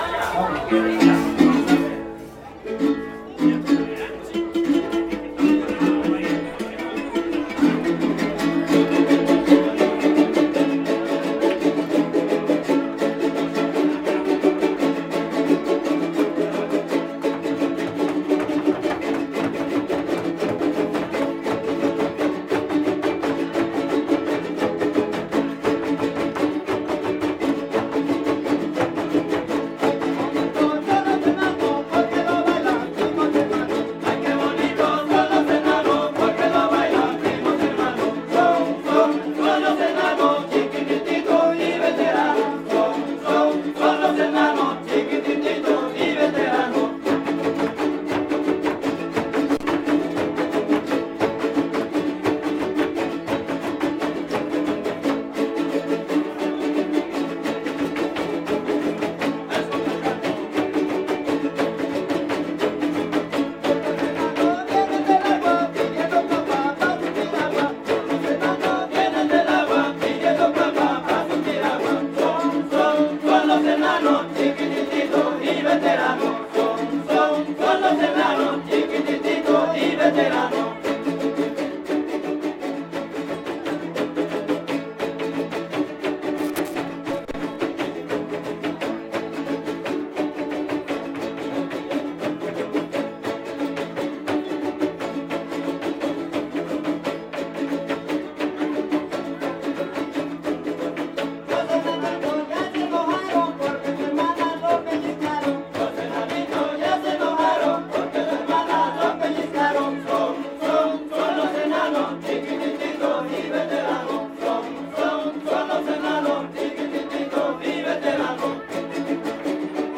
Fiesta Patronal de San Antonio de Padua